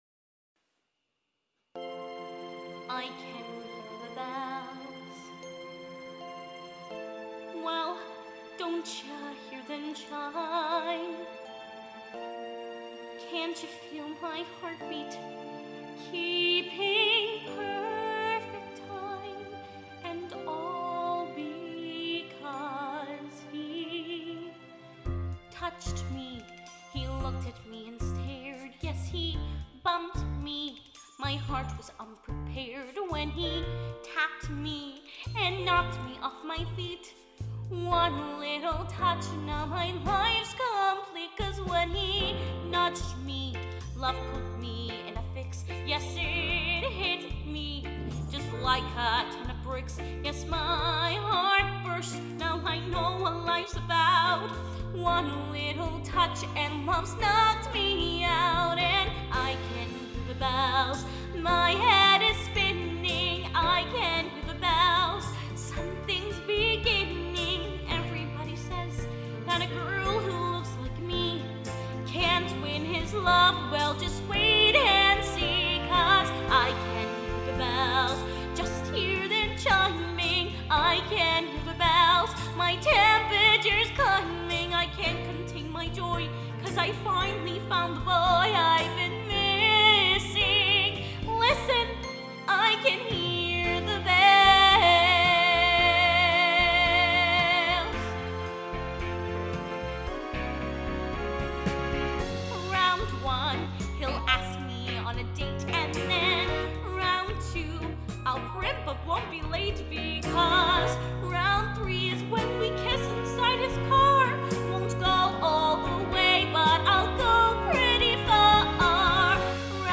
Singing range: Soprano G3 – C6.